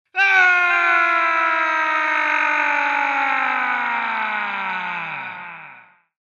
Звуки мужчины
Крик мужчины с отголоском